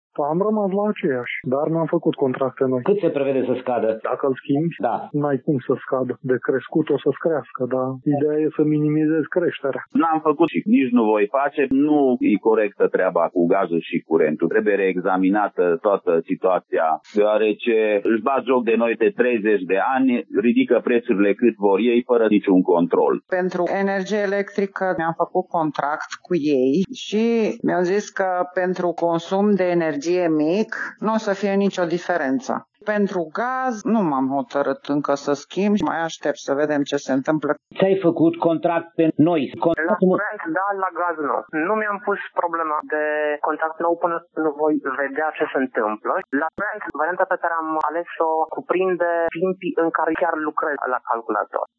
Opiniile târgumureșenilor sunt împărțite, unii au rămas la același furnizor, semnând un nou contract, alții mai așteaptă, însă sunt și dintre cei au decis să nu schimbe nimic: